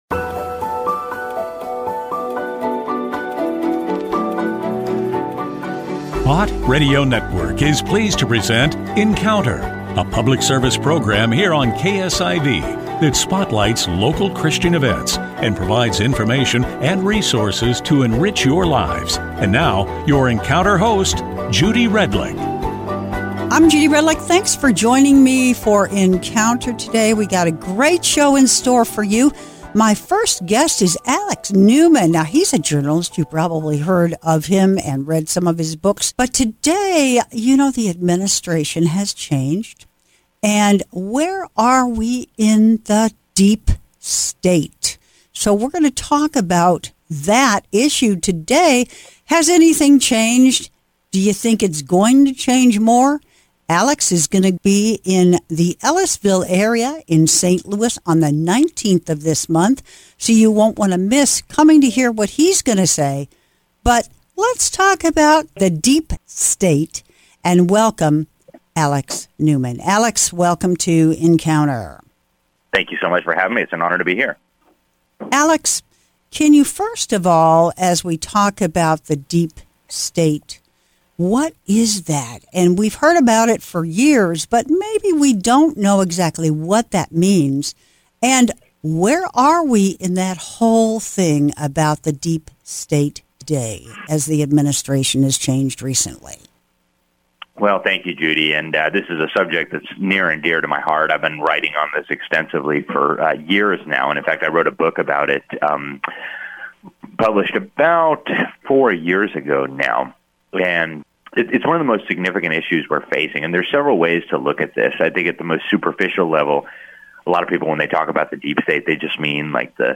Encounter Show airs every Tuesday on KSIV radio, Bott Radio Network – St. Louis.